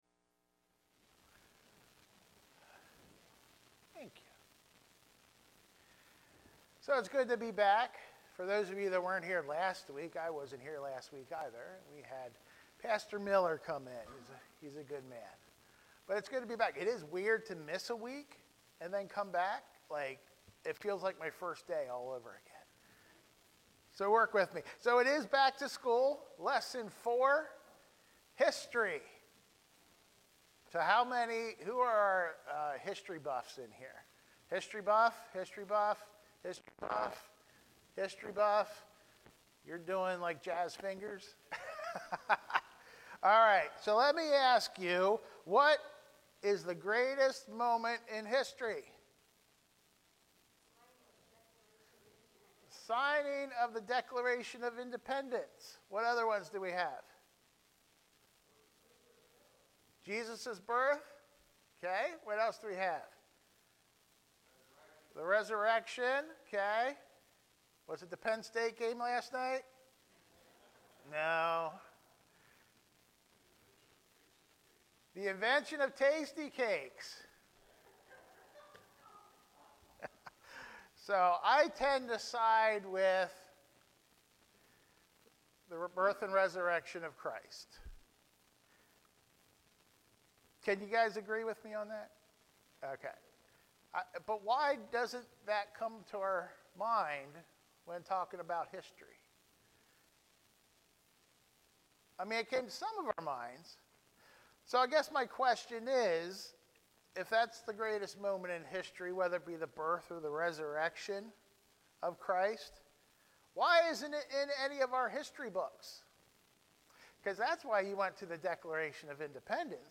Sermons | Columbia Church of God